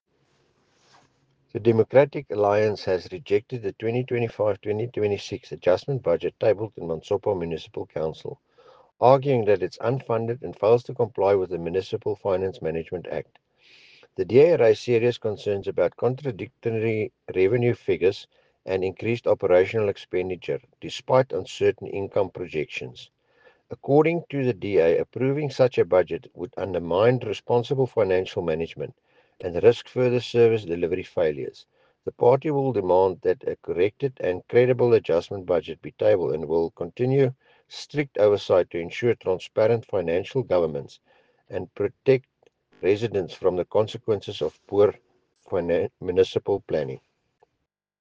Afrikaans soundbites by Cllr Dewald Hattingh and